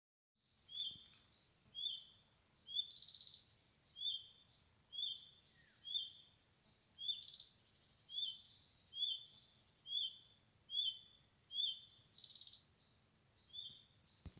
Red-breasted Flycatcher, Ficedula parva
Administratīvā teritorijaRēzeknes novads
StatusAgitated behaviour or anxiety calls from adults